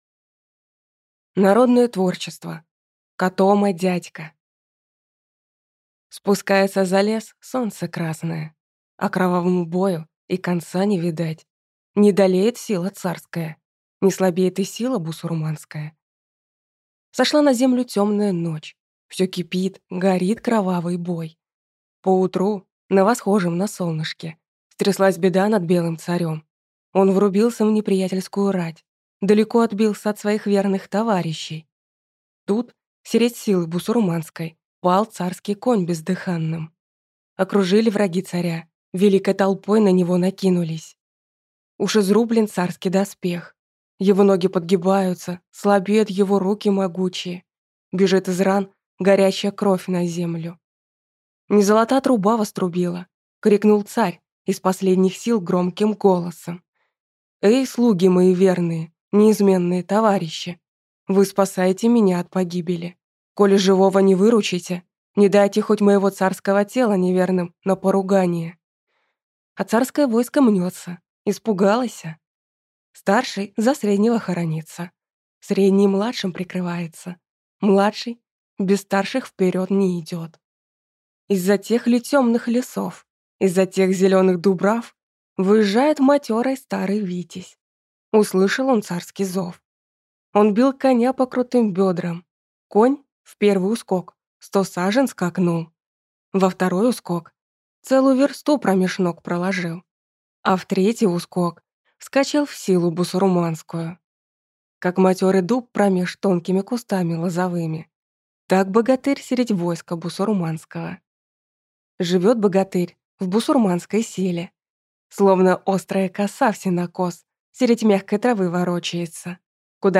Аудиокнига Котома-дядька | Библиотека аудиокниг